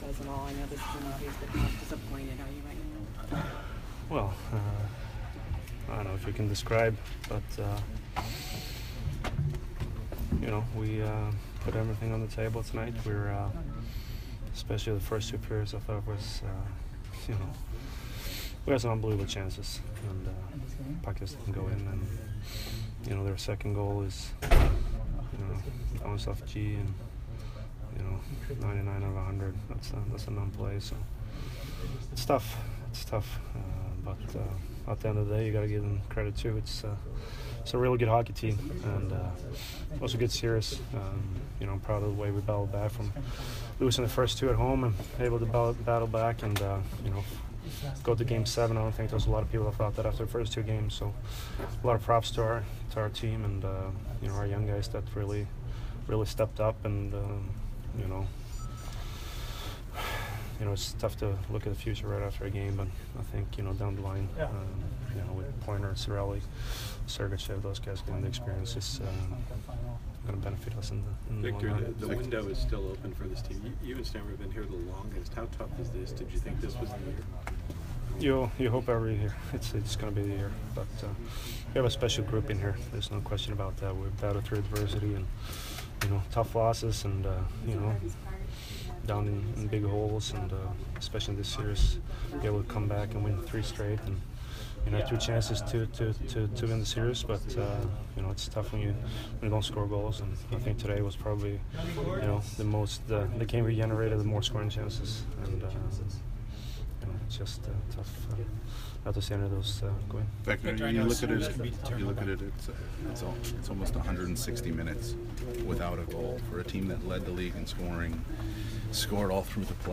Victor Hedman post-game 5/23